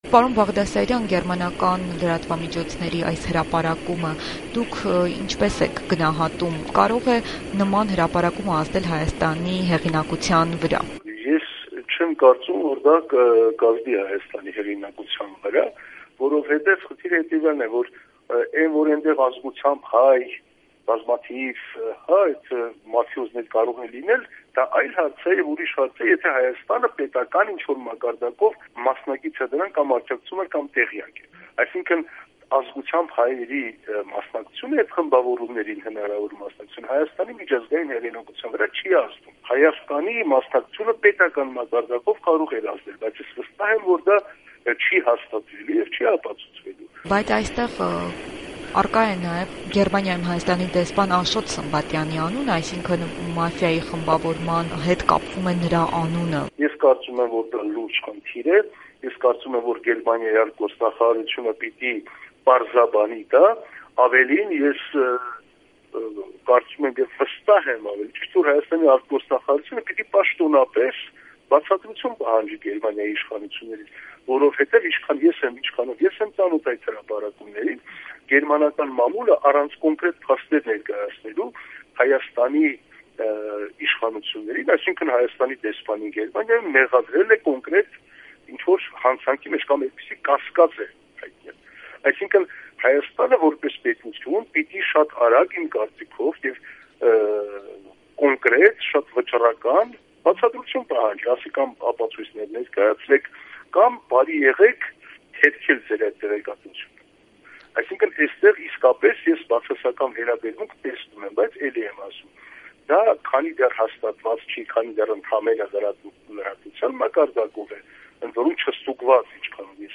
Ռեպորտաժներ
Հայերի հնարավոր մասնակցությունն էդ մաֆիոզ խմբավորումներին Հայաստանի հեղինակության վրա չի կարող ազդել. քաղաքական վերլուծաբան